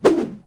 FootSwing6.wav